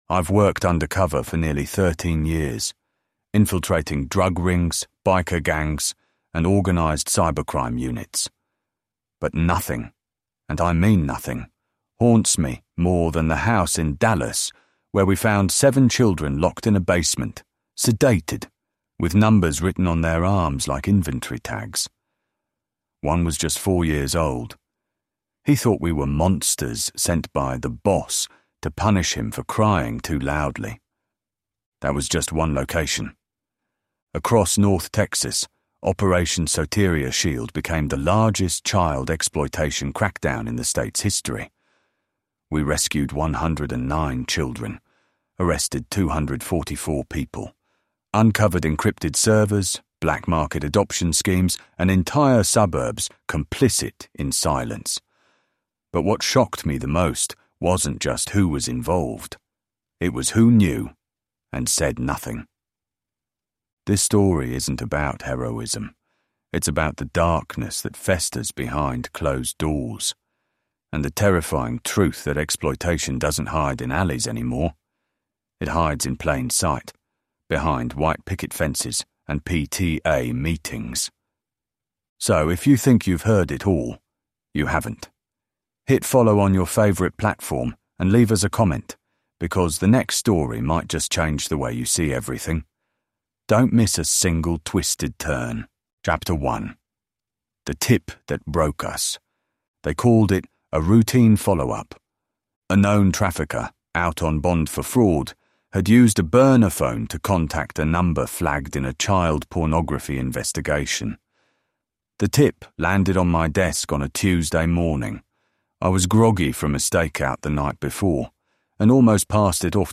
Told from the inside by an undercover officer, this emotionally raw true crime story takes you into the heart of Operation Soteria Shield: the largest child exploitation sting in Texas history.